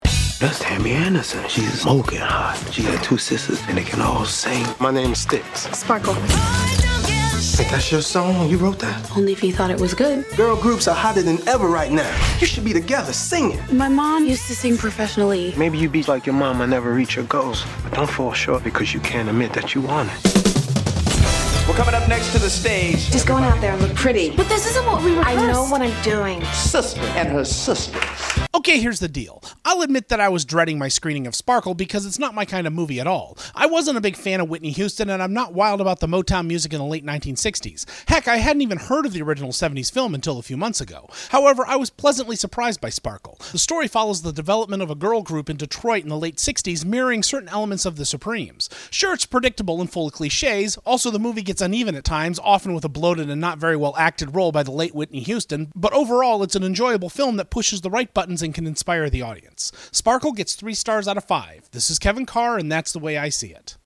Movie Review: ‘Sparkle’